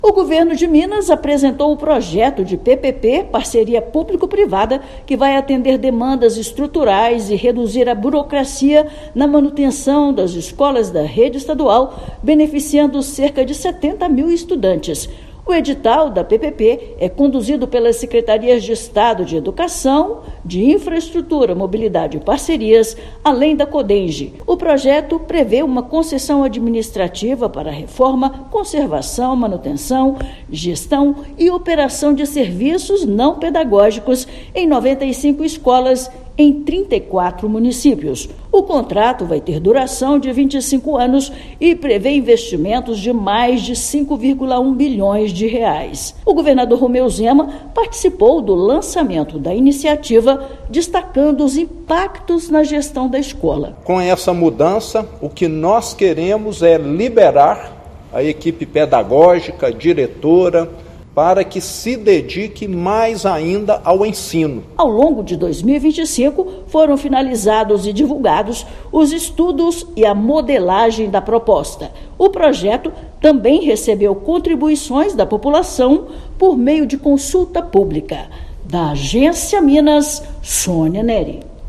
Projeto de Parceria Público-Privada vai dar mais agilidade à resolução de demandas estruturais em 95 unidades de ensino em Minas Gerais. Ouça matéria de rádio.